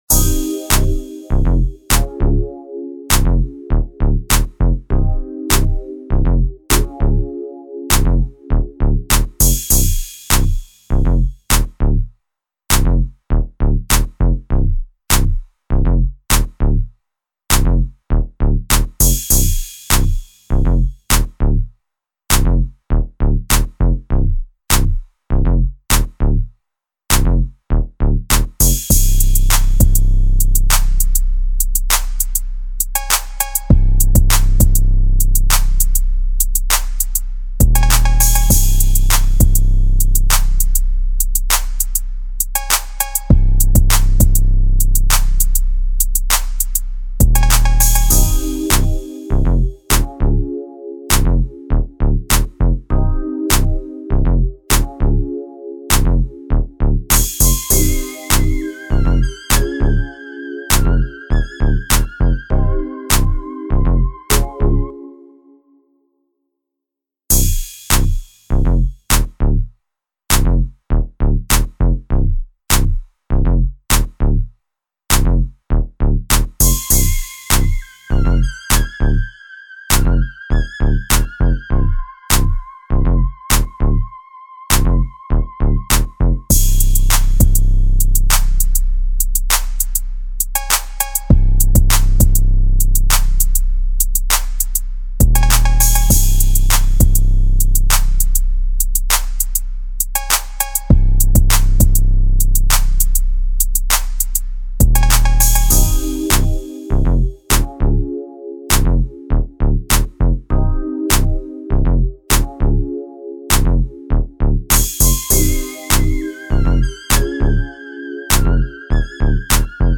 West Coast Instrumental